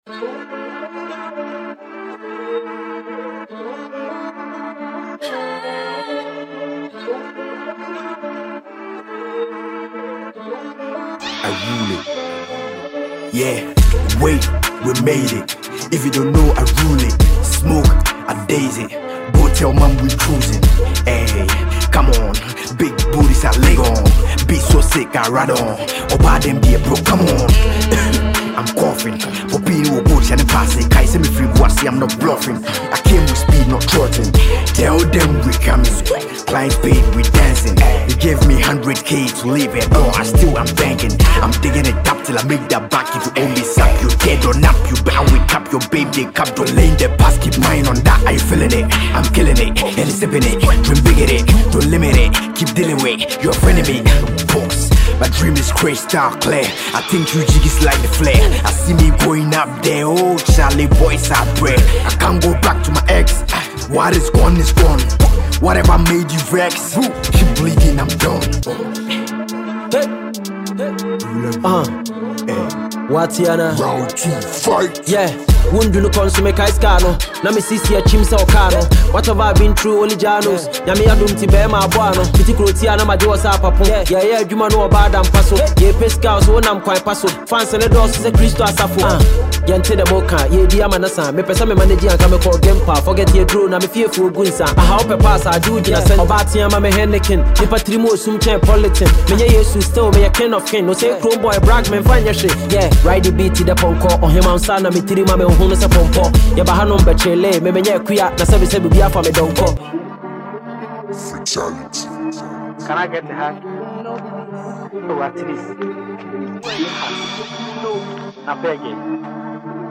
With both artists dropping heavy punches over a gritty beat
rap battle